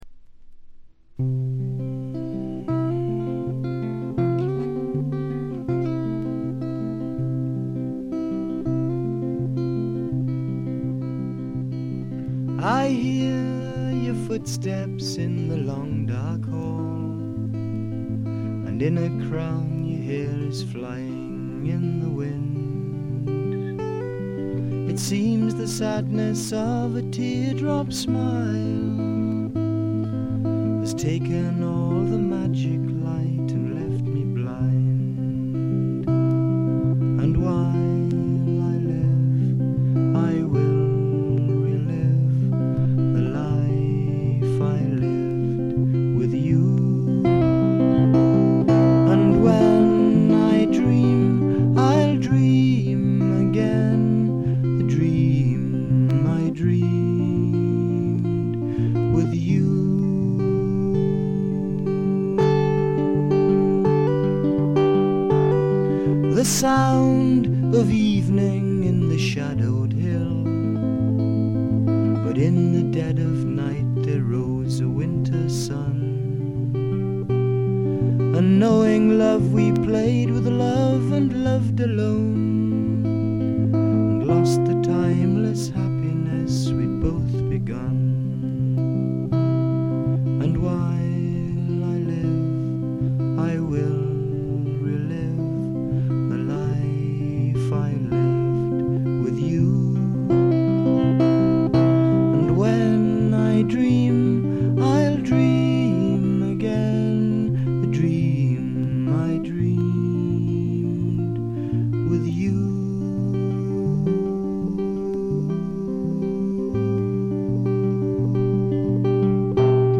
わずかなノイズ感のみ。
内容的にはおそろしく生々しいむき出しの歌が聞こえてきて、アシッド・フォーク指数が異常に高いです。
試聴曲は現品からの取り込み音源です。
Vocals, Acoustic Guitar